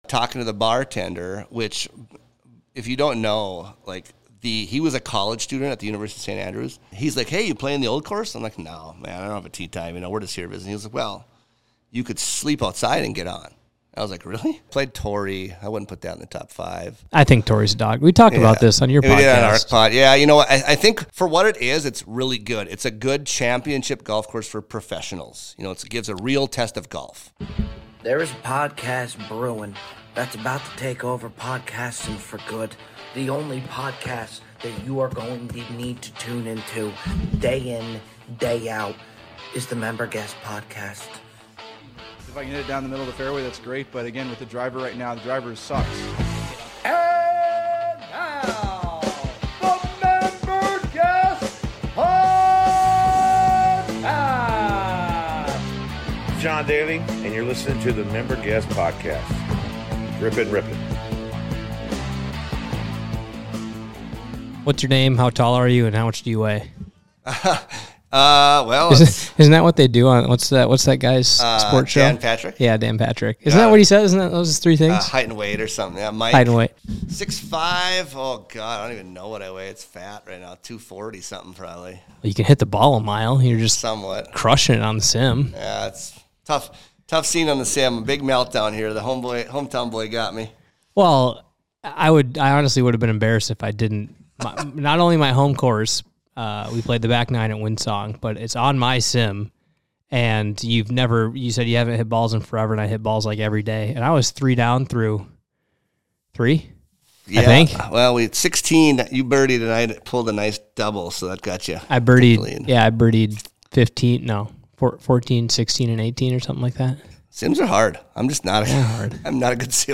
in studio to chat PGA, some of their top courses played and a slew of other golf topics.